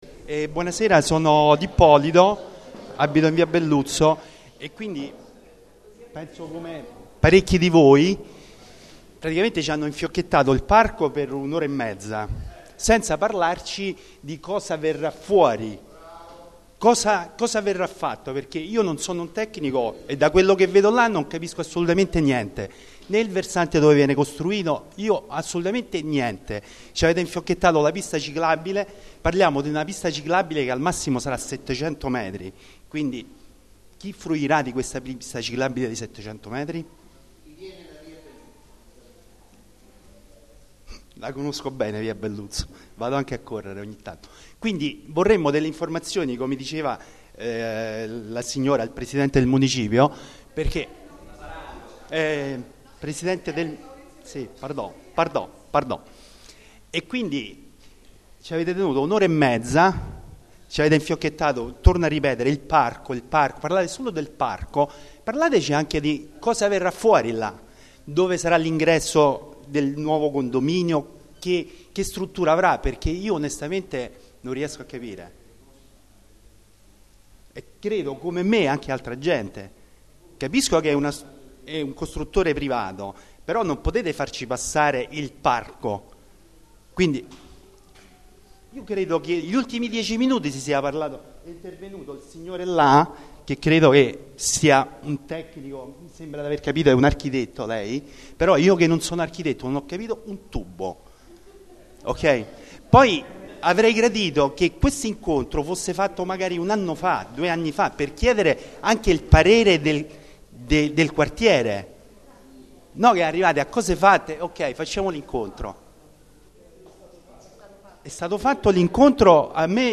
Registrazione integrale dell'incontro svoltosi il 17 luglio 2014 presso la Parrocchia Sacra Famiglia al Portuense, in via Filippo Tajani 10
residente